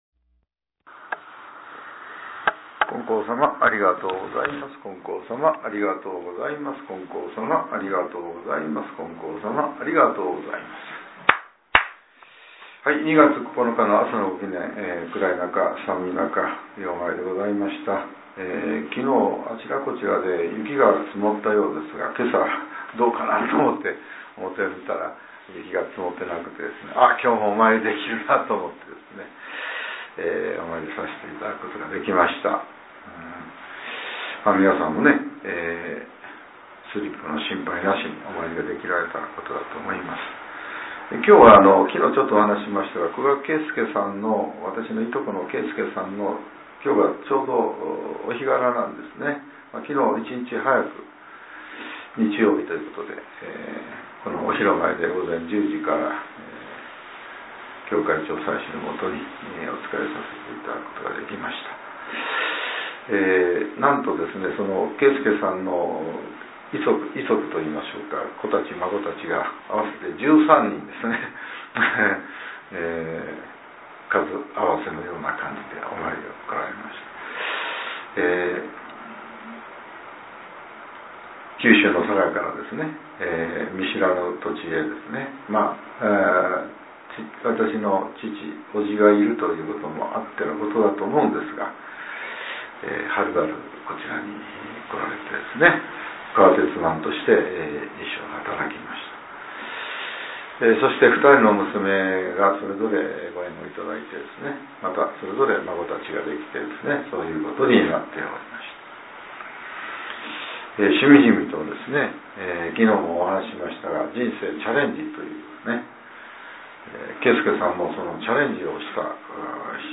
令和８年２月９日（朝）のお話が、音声ブログとして更新させれています。